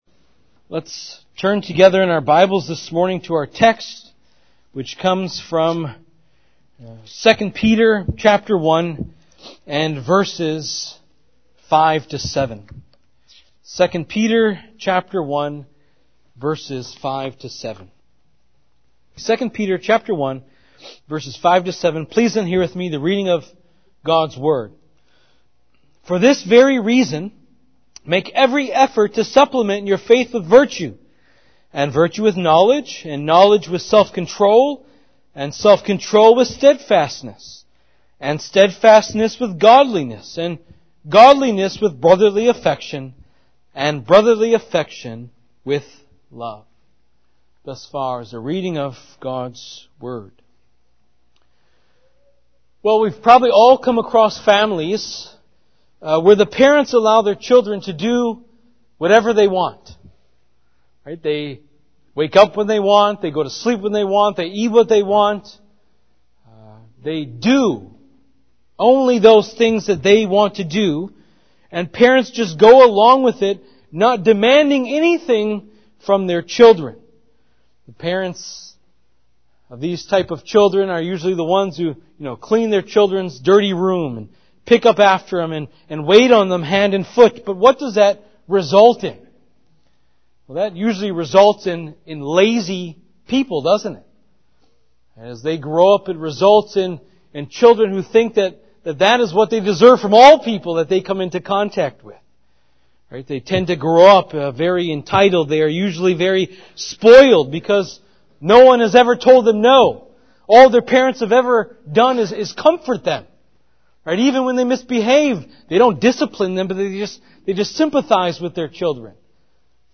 2-peter-1-5-7-sermon-living-our-lives-to-honor-christ.mp3